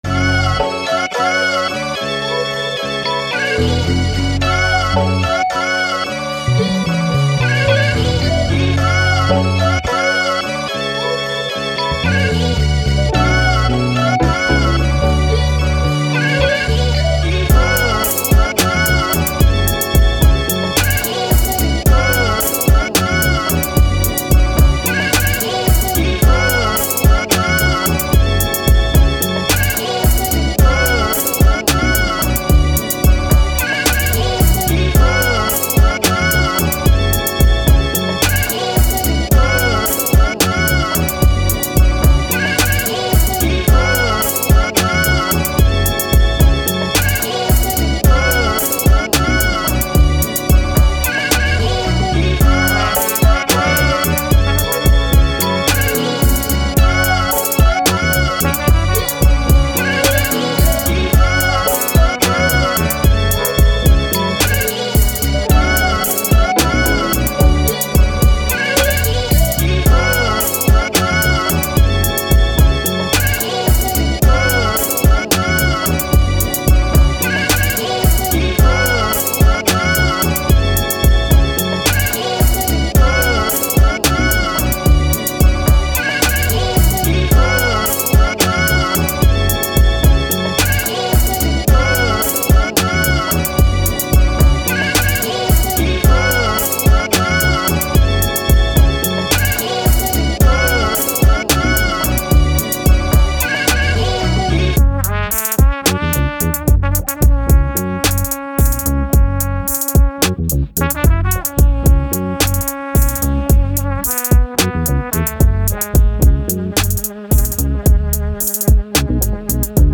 Hip Hop, Trap, Upbeat, Energetic, Action